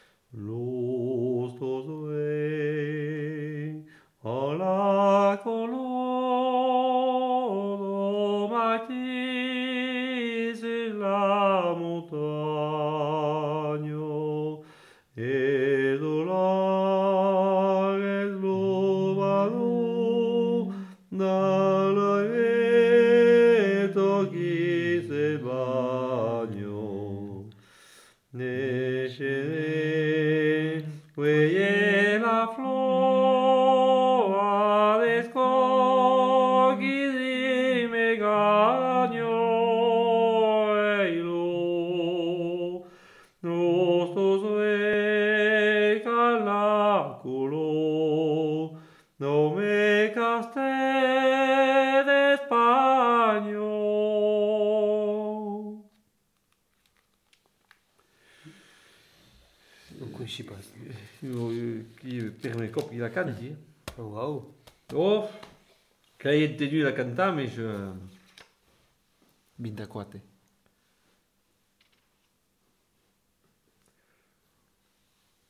Lieu : Bénac
Genre : chant
Effectif : 1
Type de voix : voix d'homme
Production du son : chanté